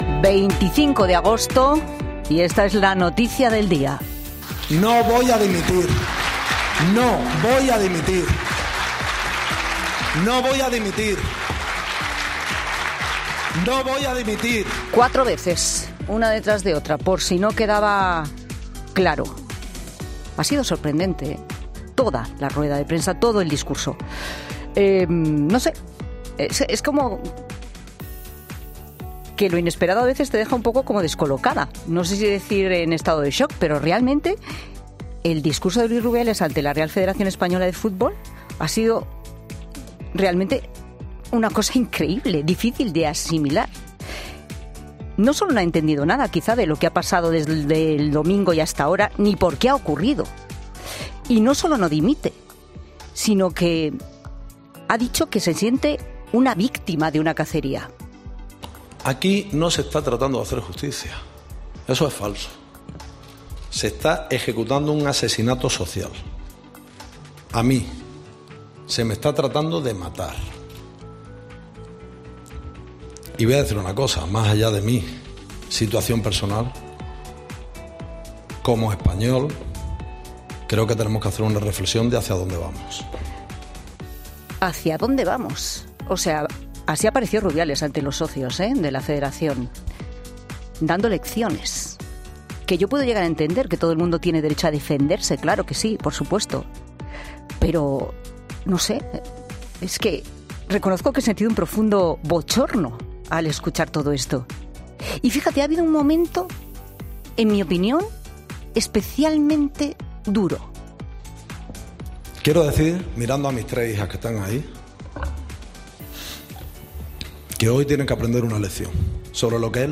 Monólogo de Pilar Cisneros